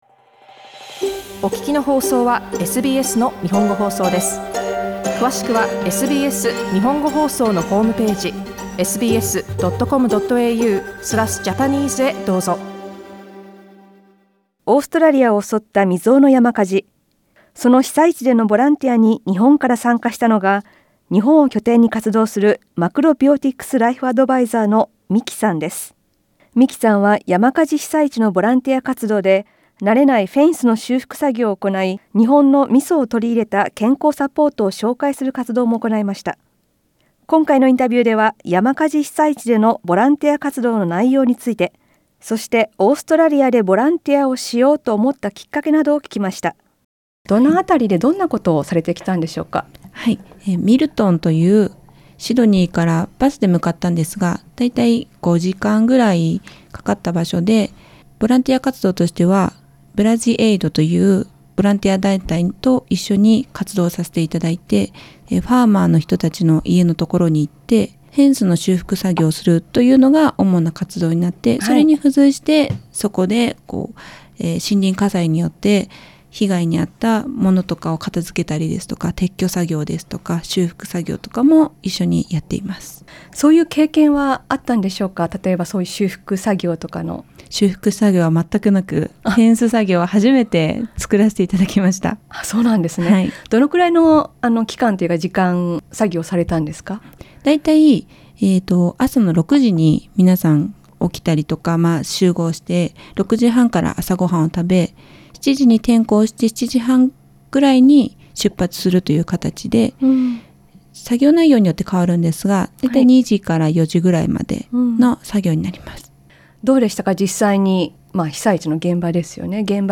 インタビューでは、ボランティア活動の様子や、シドニー行きをきめたきっかけなどを聞きました。